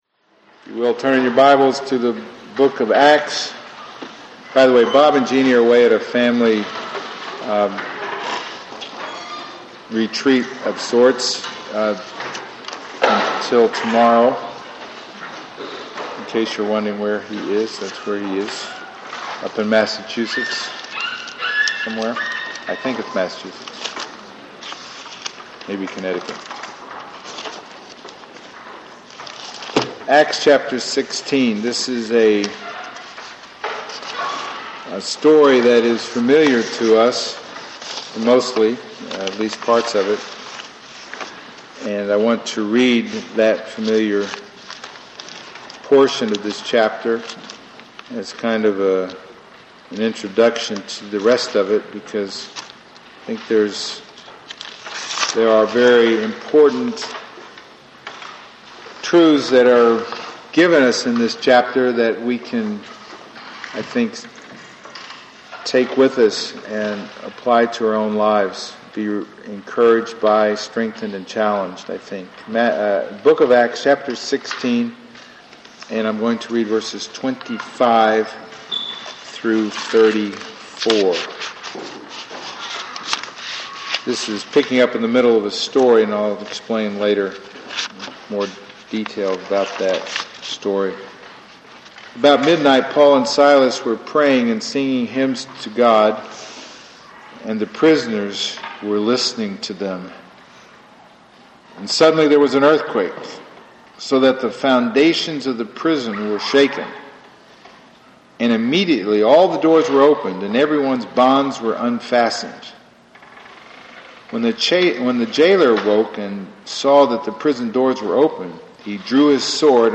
Acts Passage: Acts 16:1-40 Service Type: Sunday Morning %todo_render% « Salvation for All